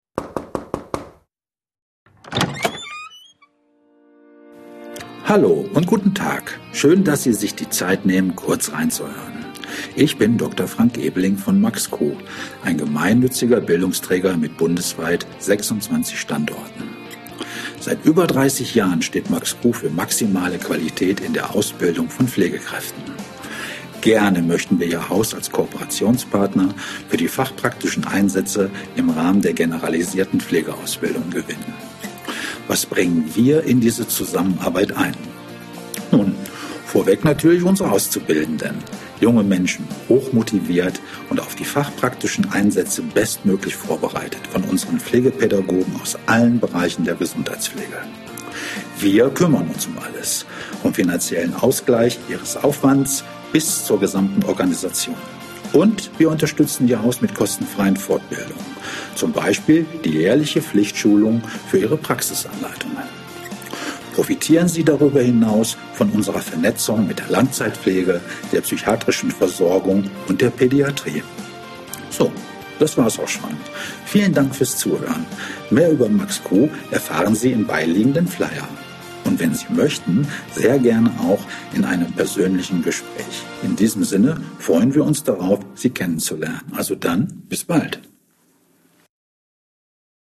maxQ-Werbejingle_V1.mp3